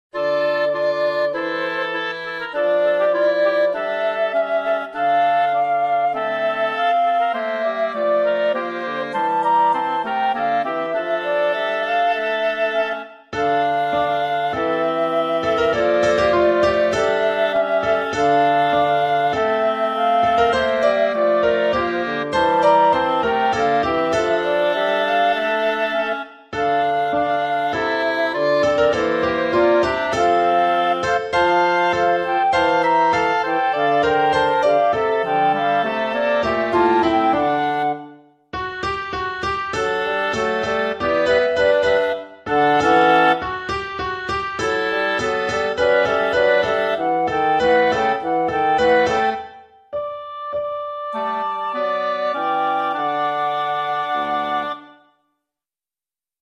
1.-La-ciutat-sense-amics-Instrumental.mp3